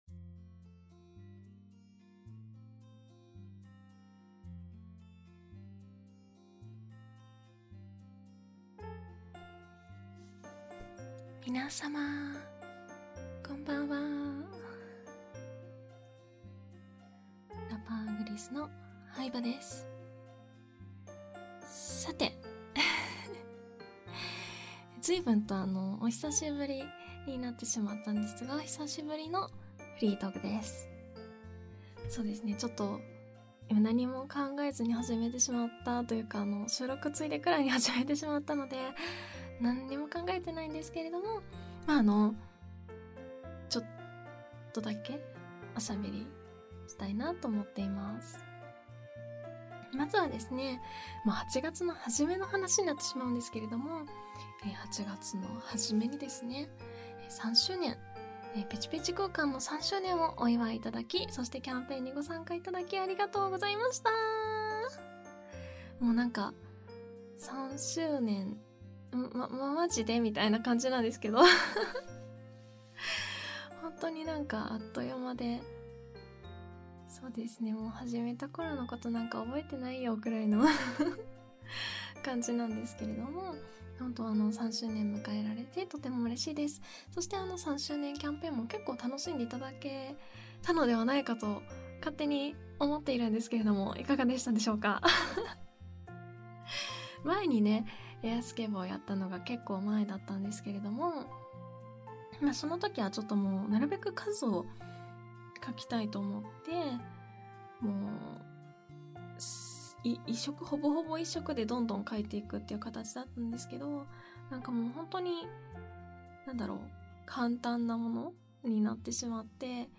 I spend my life with no much time for streaming, but I wish I could output myself little by little, so around once a week under the name of ”FREE TALK”, I would like to make a voice post such as looking back my past week with almost no editing (though it includes noise cut and BGM)!